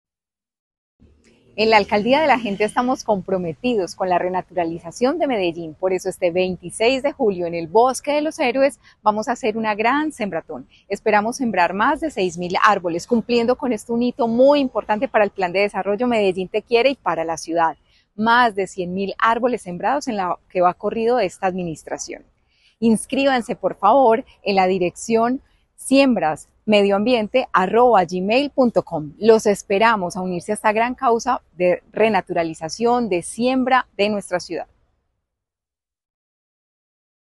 Declaraciones de la secretaria de Medio Ambiente, Marcela Ruiz Saldarriaga.
Declaraciones-de-la-secretaria-de-Medio-Ambiente-Marcela-Ruiz-Saldarriaga..mp3